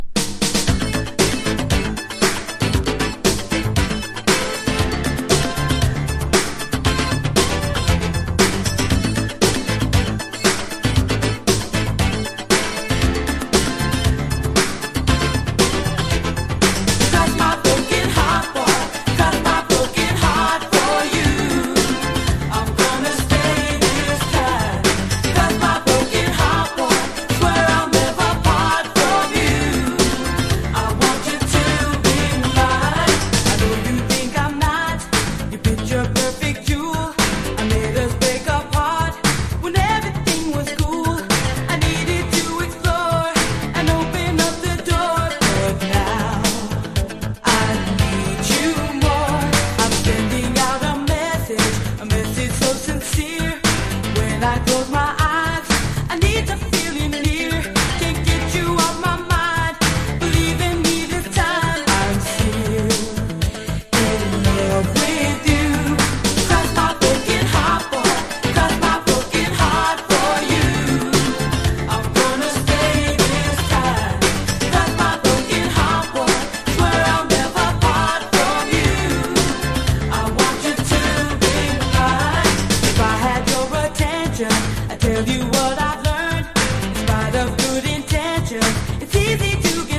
軽快な80'Sシンセ・ディスコ!!
# FUNK / DEEP FUNK# DISCO